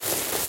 脚步声 " 脚步声草 5
描述：在草样品的唯一脚步。